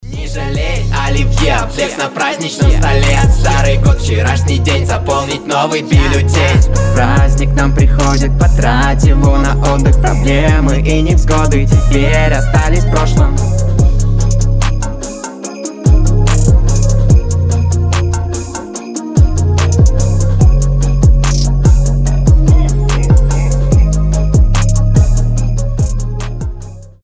• Качество: 320, Stereo
позитивные
веселые
праздничные